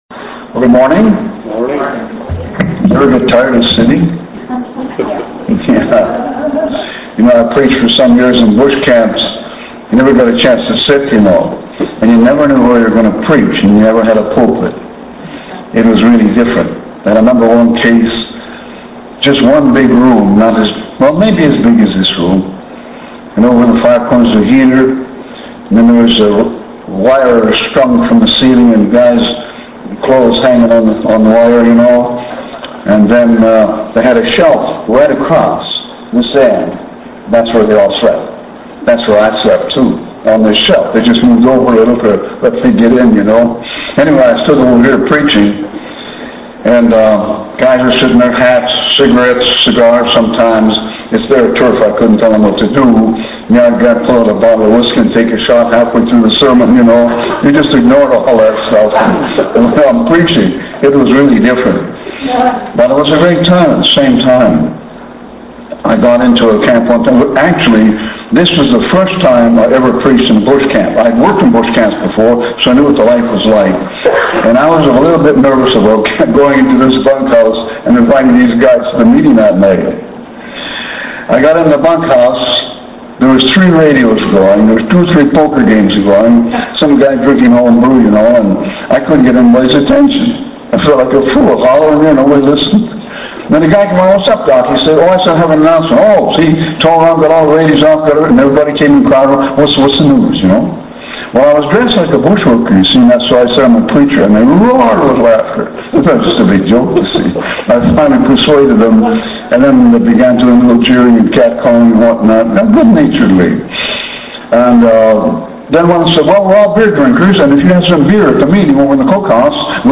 In this sermon, the speaker reflects on a personal experience where they felt deceived and foolish. They emphasize the importance of not giving any room to the devil and not allowing negative influences into one's life.